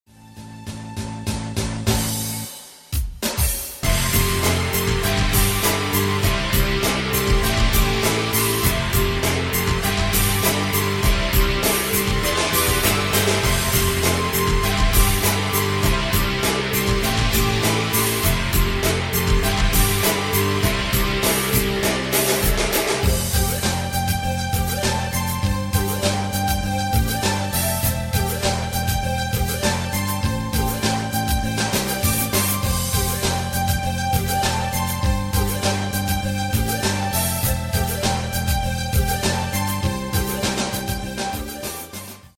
• Качество: 192, Stereo
инструментальные